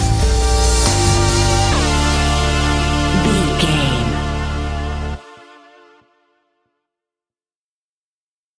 Rising Heavy Synth Stinger.
Aeolian/Minor
D
ominous
dark
eerie
synthesiser
percussion
drums
electronic music